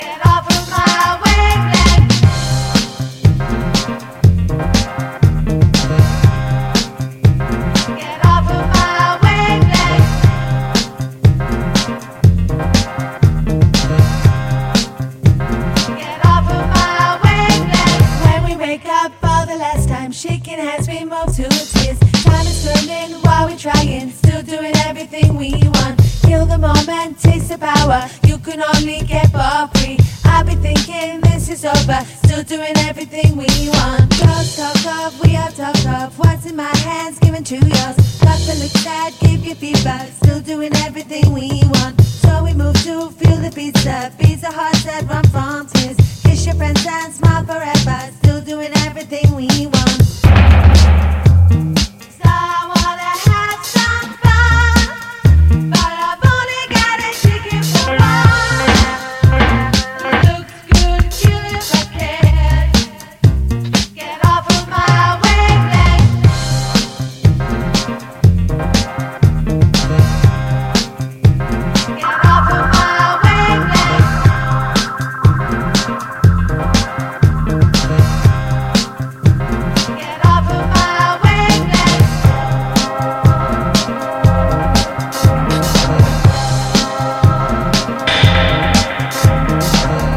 punk-funk
Disco Electro House